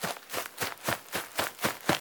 run.mp3